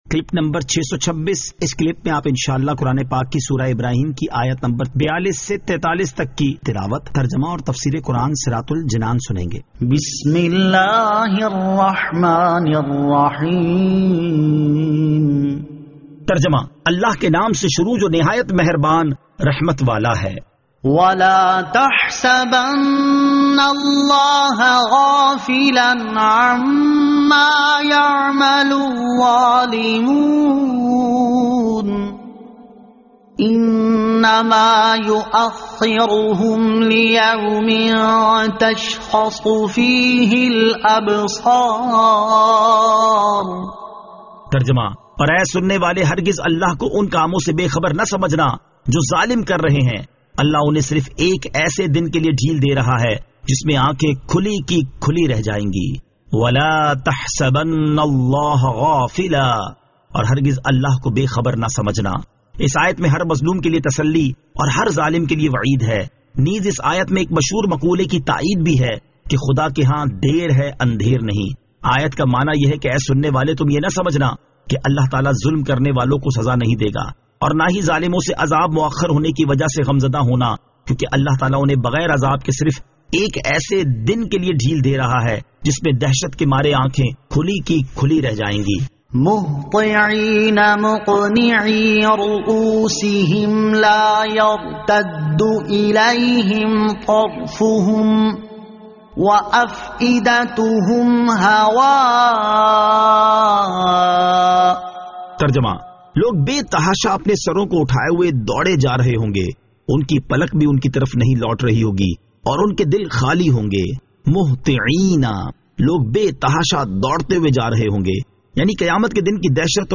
Surah Ibrahim Ayat 42 To 43 Tilawat , Tarjama , Tafseer
2021 MP3 MP4 MP4 Share سُوَّرۃُ ابٗرَاھِیم آیت 42 تا 43 تلاوت ، ترجمہ ، تفسیر ۔